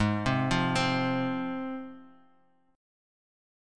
G#6 Chord
Listen to G#6 strummed